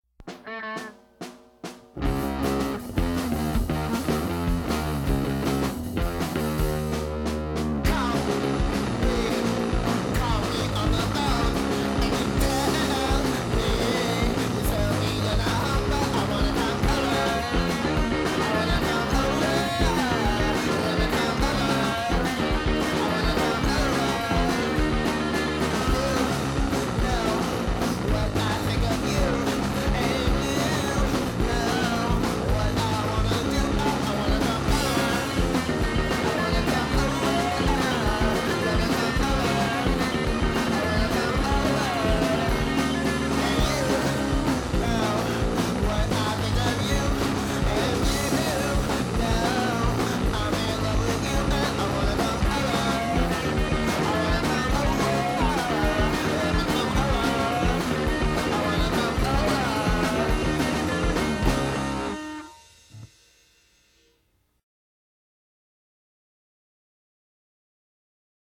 La scène garage pédé de la Bay Area
Plus punk, moins girls group